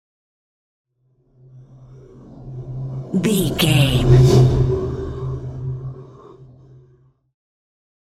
Scifi whoosh pass by deep
Sound Effects
futuristic
pass by